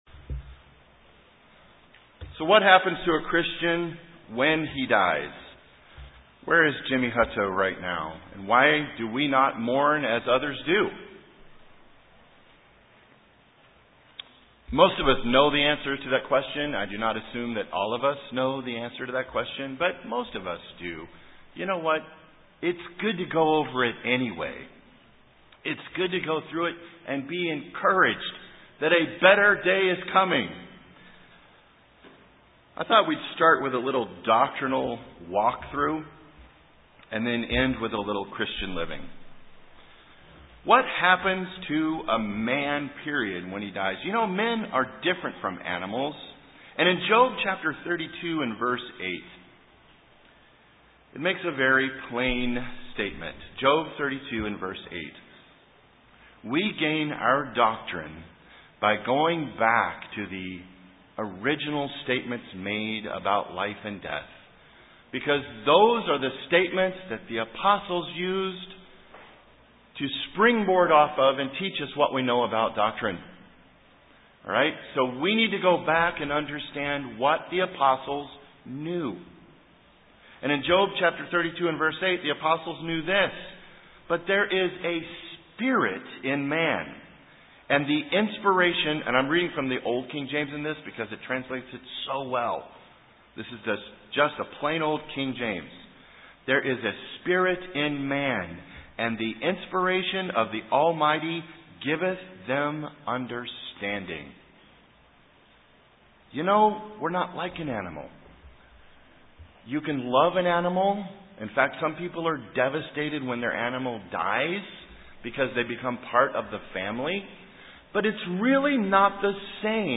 This sermon helps us remember the doctrine of what happens when we as children of God, die.